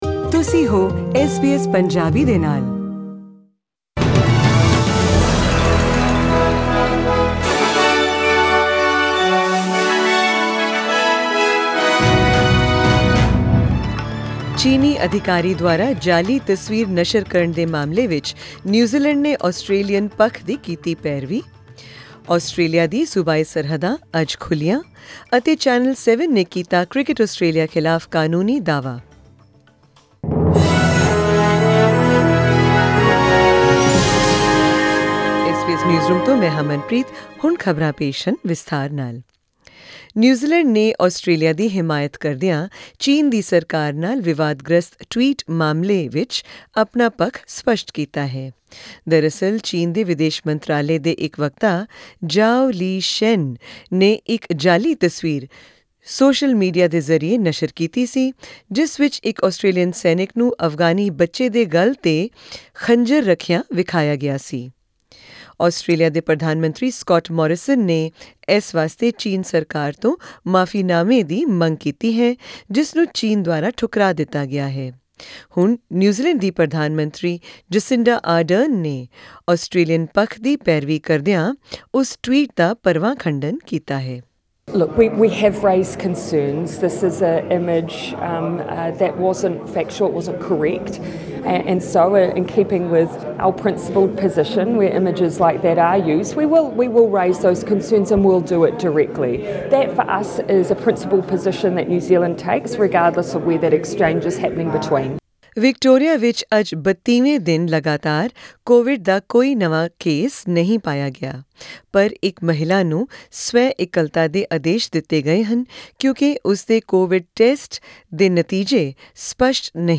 In tonight's SBS Punjabi news bulletin: